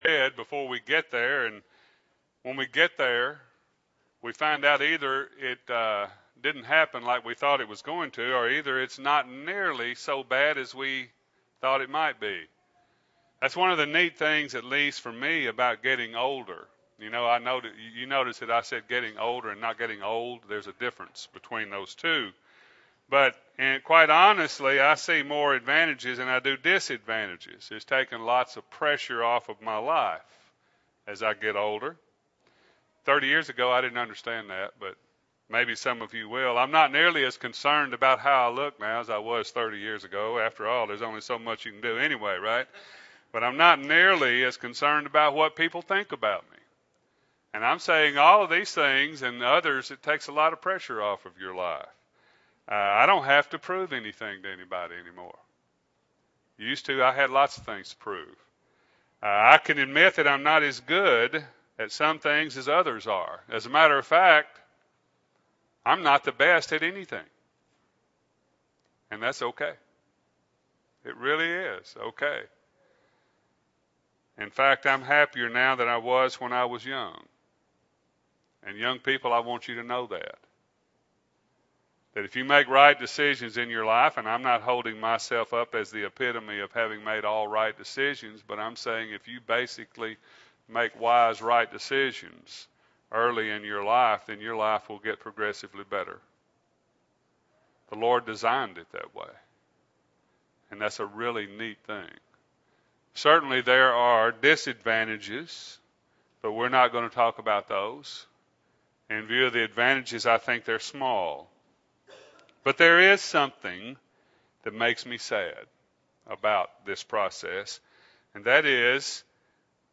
2008-08-03 – Sunday AM Sermon – Bible Lesson Recording